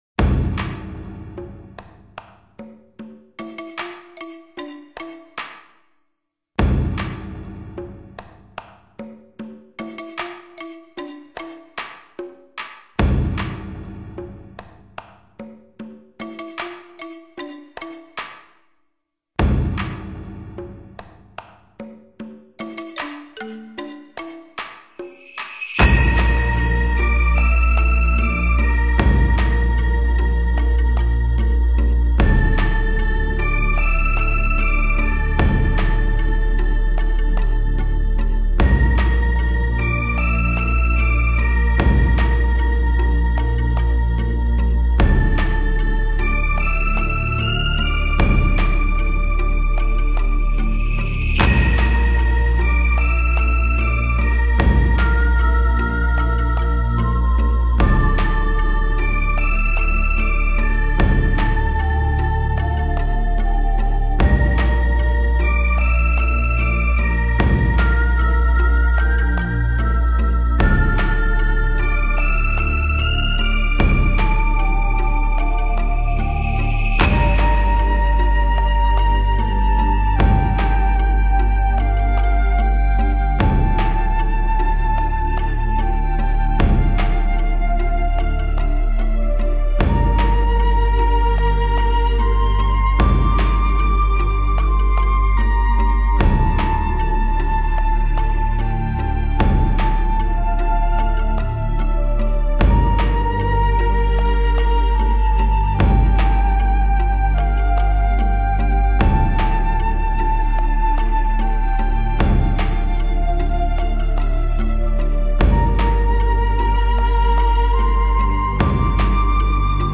专辑种类：电影原声
它的音乐是动态庞大而撼人心魄的。全部七首乐曲旋律看似都十分简单，然而正是这种简单，才蕴涵着博大、深奥。